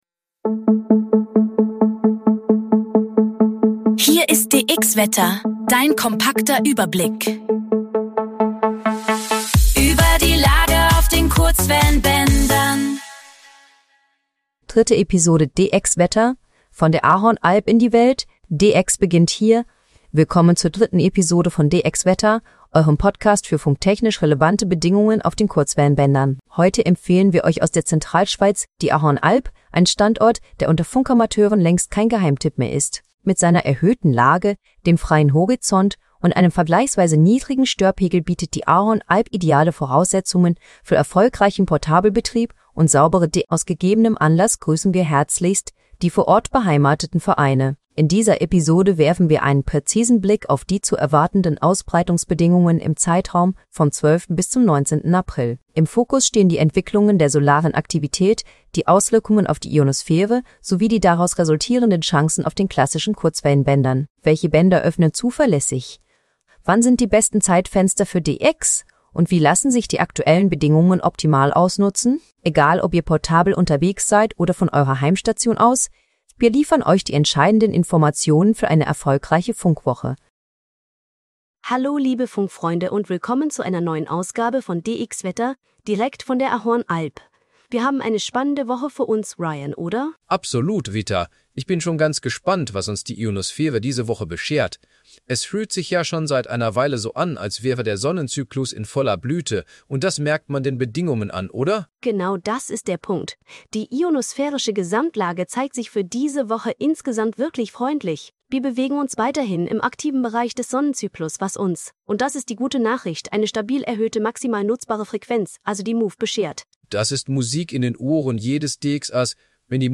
KI-generierter Inhalt - aktuell für die Region Ahorn Alp LU